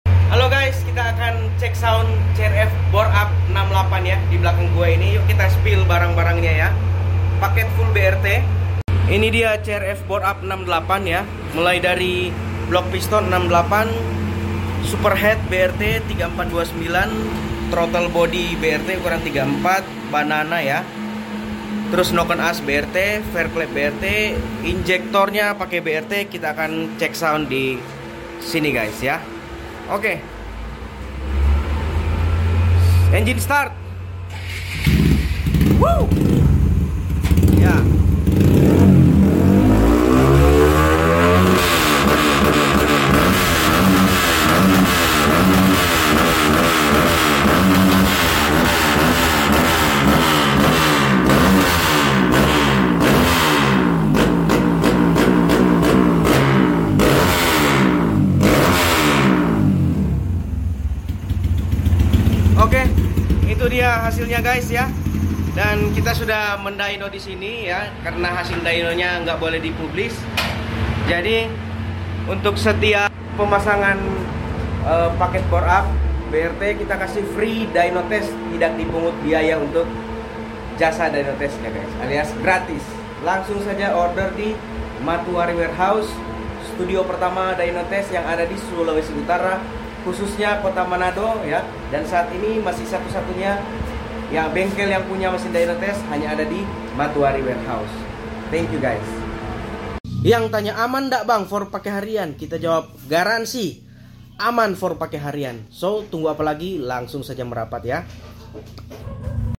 DYNOTEST..